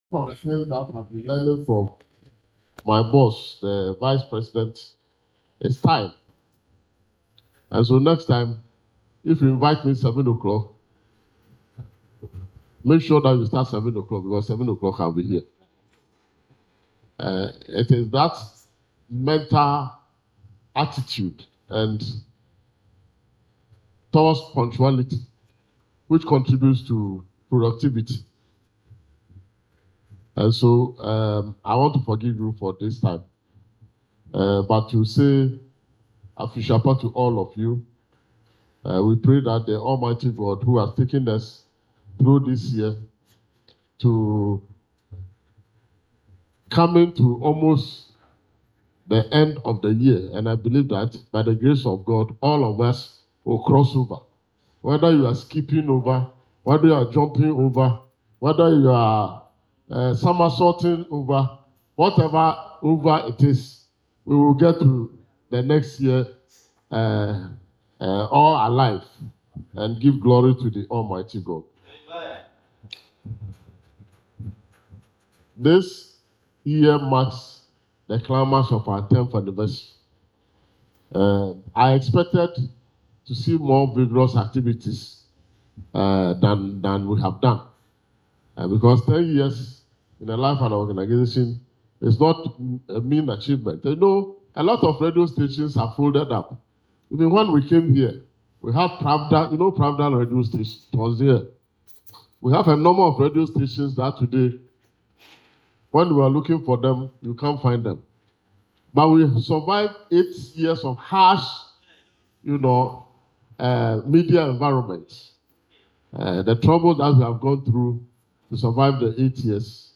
The event, held at the Tinsel Town Hotel and Bar at Lashibi in the Greater Accra Region, brought together staff and their loved ones for an evening of music, food, drinks, and celebration.
SPEECH-CHAIRMAN.mp3